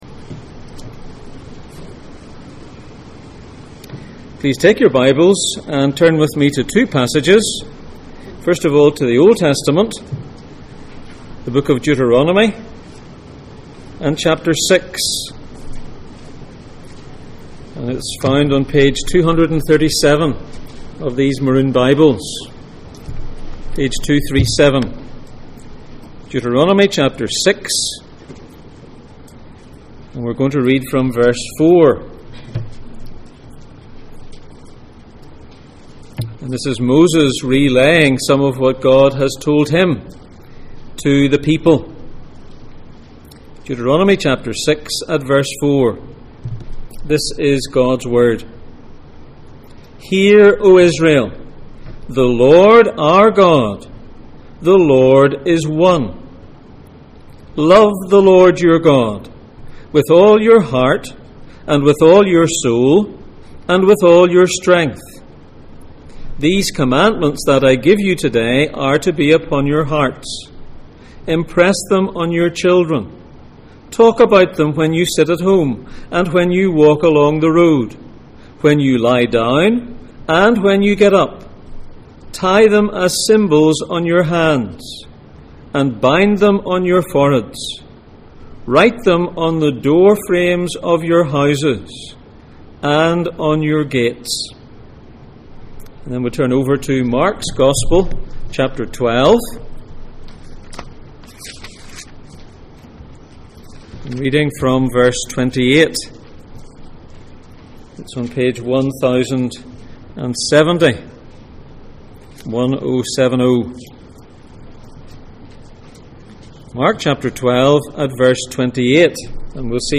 Passage: Mark 12:28-34, Deuteronomy 6:4-9 Service Type: Sunday Morning